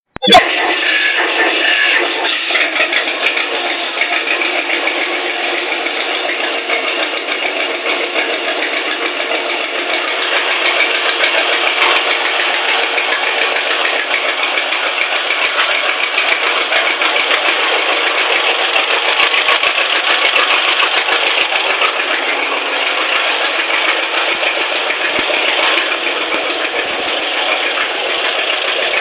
John Deere Tractor Ringtone
john_deere_tractor.mp3